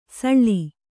♪ saḷḷi